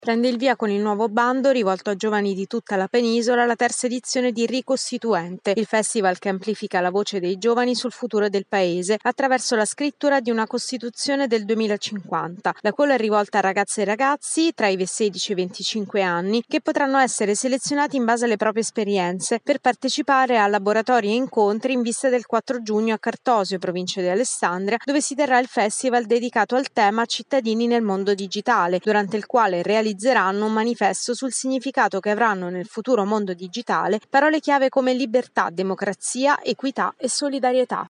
servizio-ricostituente.mp3